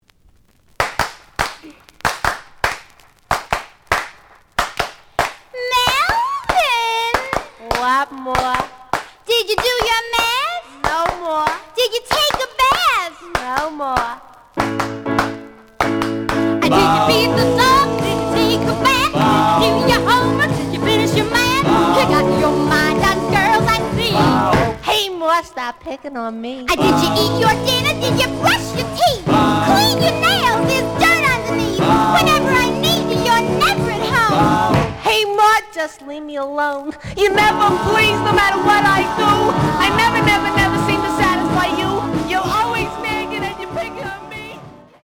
The audio sample is recorded from the actual item.
●Genre: Rhythm And Blues / Rock 'n' Roll
Slight damage on both side labels. Plays good.)